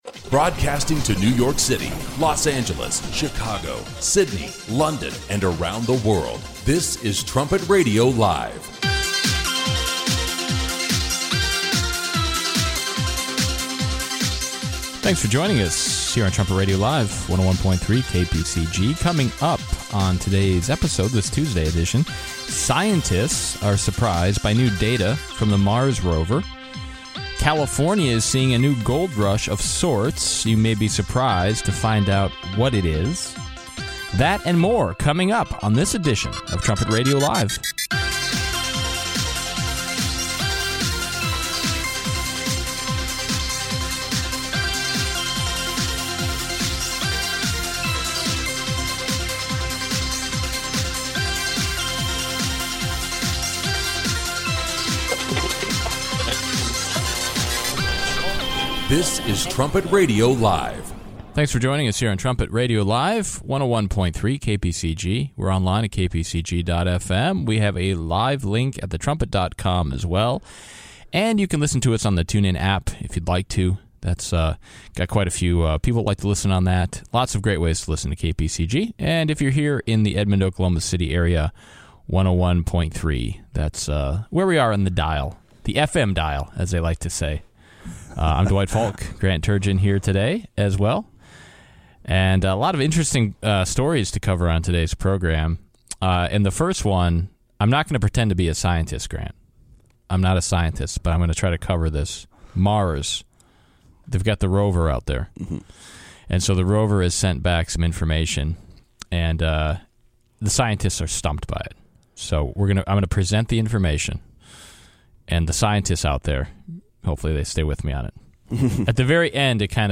Trumpet Radio Live seeks to provide God’s view of the current headlines in an informative, stimulating, conversational and occasionally humorous way.